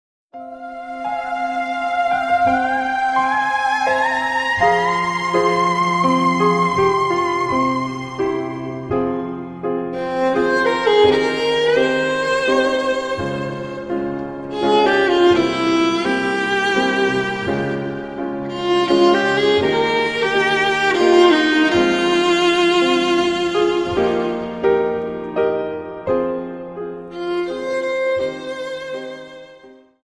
Dance: Waltz